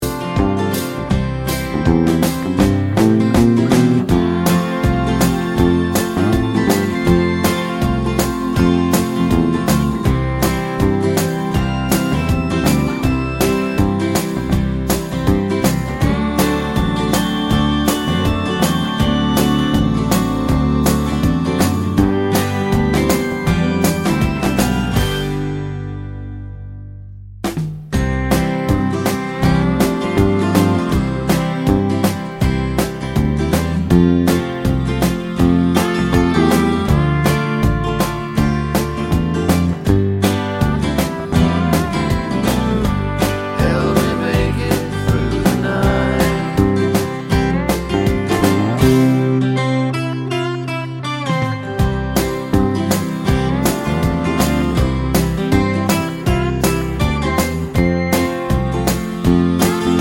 no Backing Vocals Country (Male) 2:31 Buy £1.50